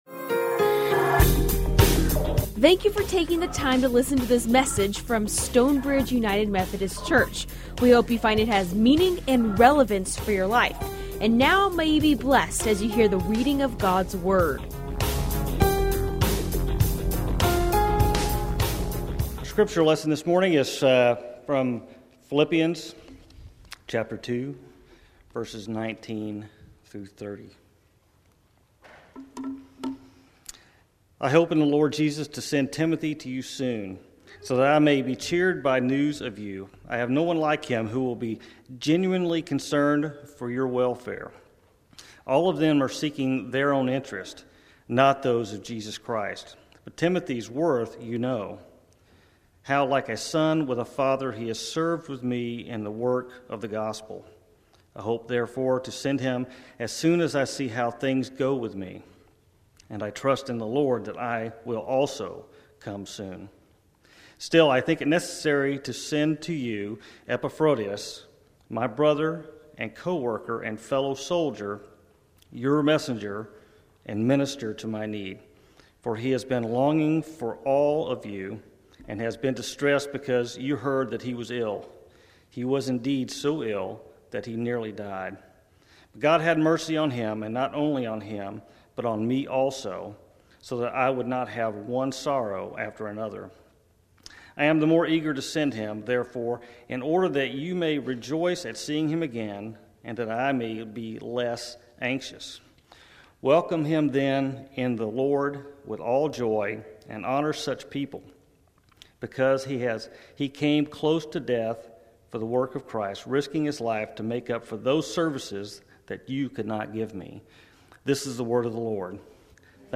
Recorded live at Stonebridge United Methodist Church in McKinney, TX.